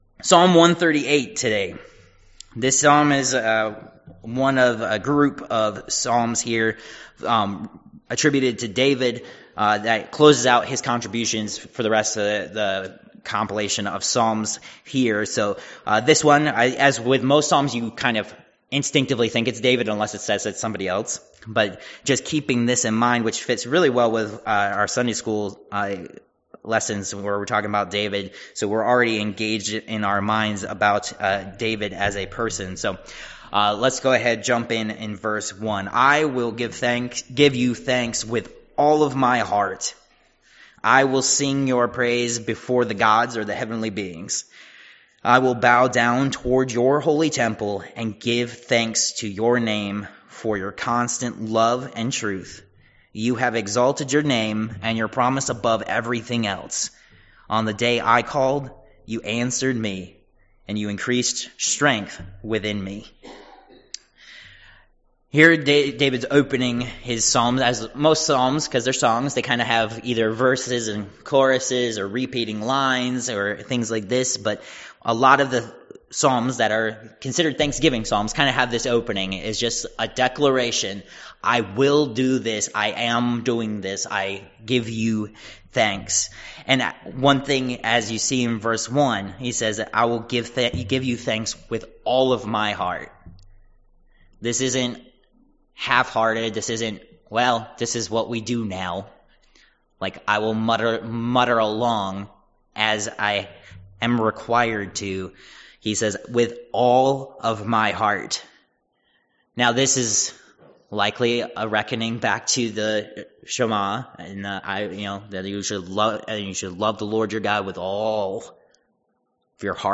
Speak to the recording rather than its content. Psalm 138 Service Type: Worship Service Topics: Thanksgiving « Protected